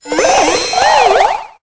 Cri de Blancoton dans Pokémon Épée et Bouclier.